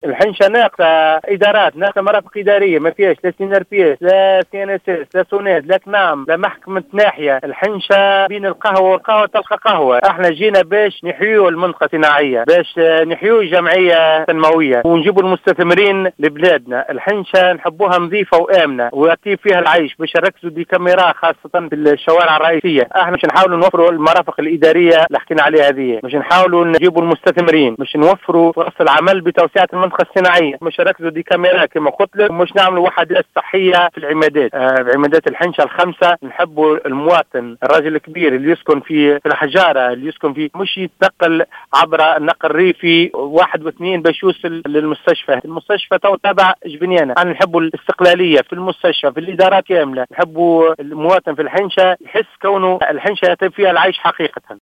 في تصريح للجوْهرة أف أم